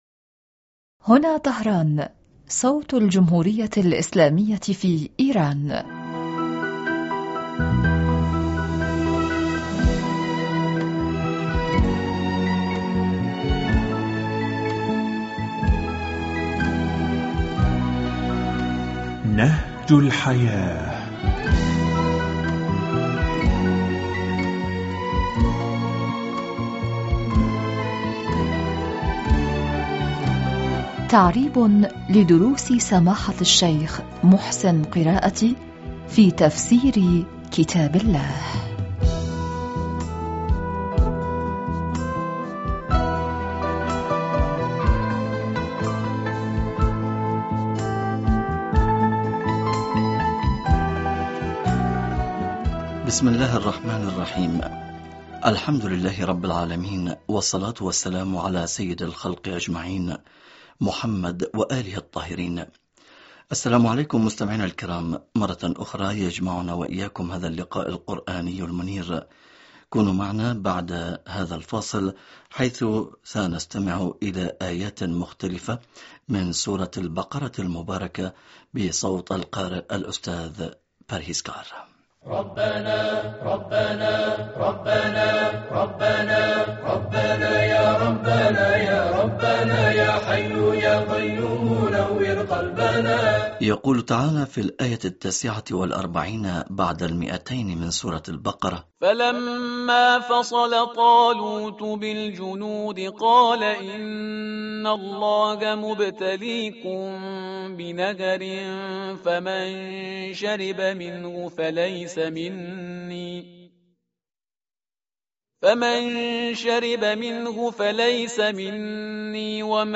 هذا اللقاء القرآني المنير